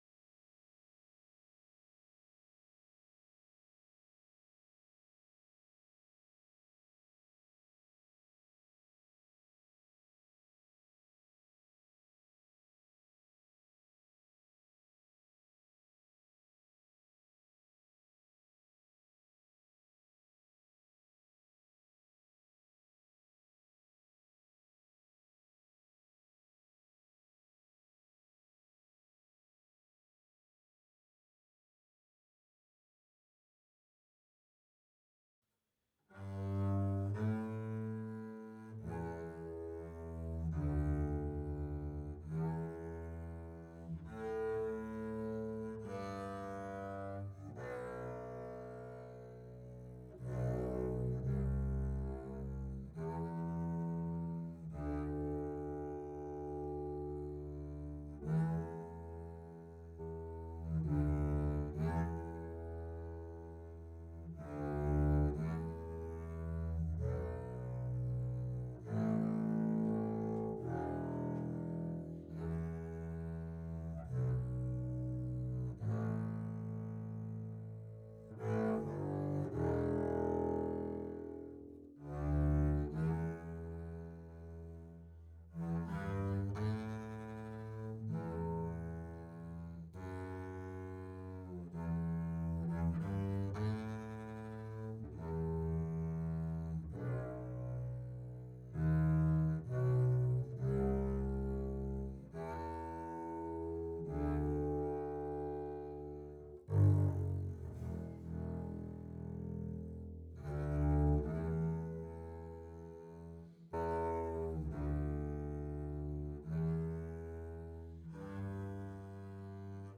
Toward Tomorrow - Bass - Stereo.wav